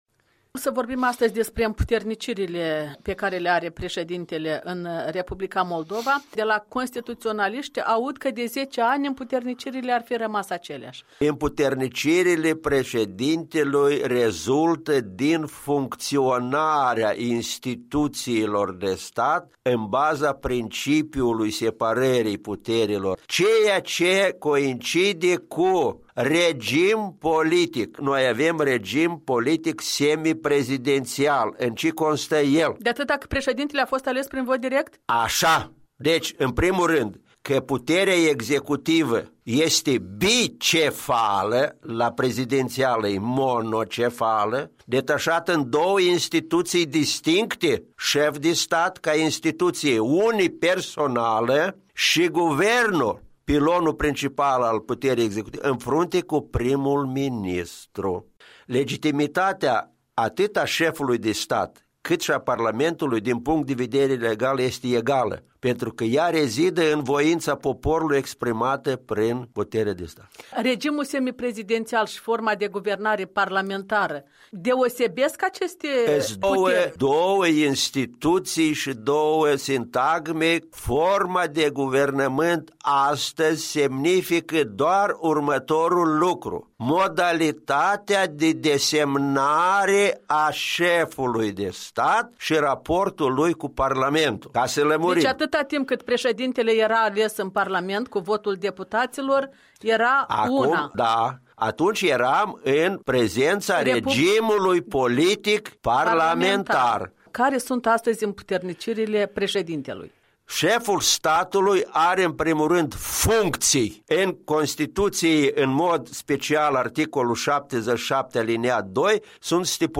Un dialog despre prerogativele președintelui cu un expert constituțional, profesor universitar.